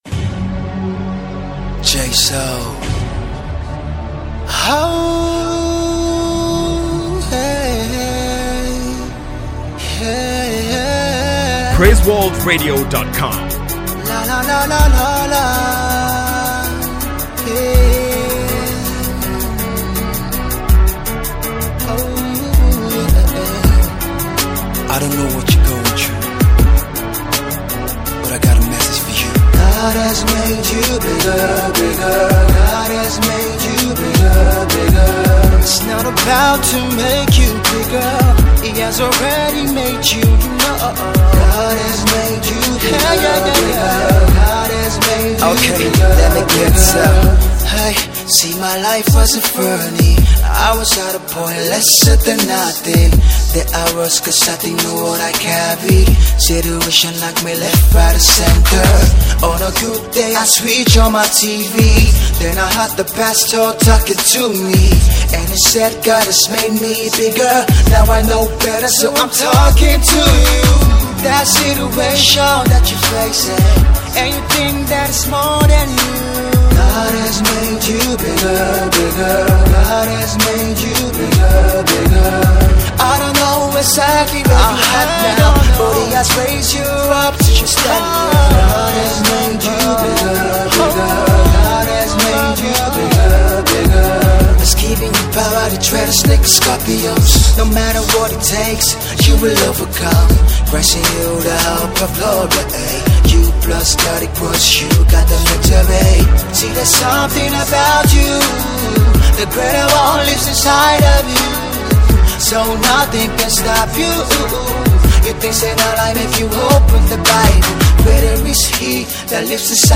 gospel tunes